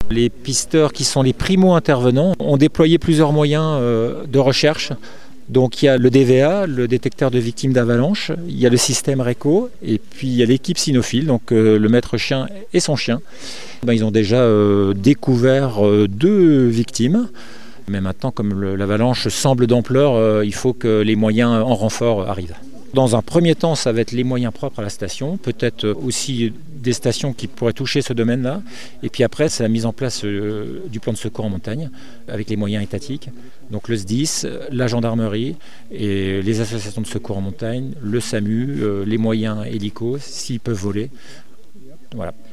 Un exercice de sécurité civile d’ampleur était organisé sur le domaine skiable du Grand-Massif ce jeudi, dans le secteur des Carroz.